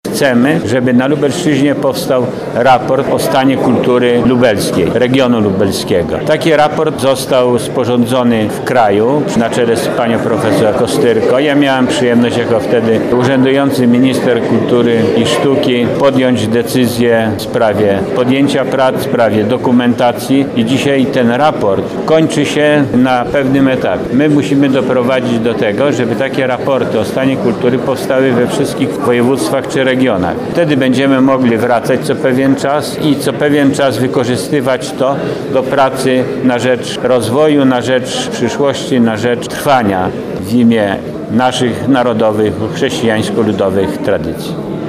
Pełnomocnik Wojewody Lubelskiego do Spraw Kultury Ludowej, Rękodzieła Ludowego i Artystycznego Zdzisław Podkański w rozmowie z dziennikarzami podkreślił, że to co dzieje obecnie w kulturze ludowej w naszym regionie powinno się doczekać dokładnego opracowania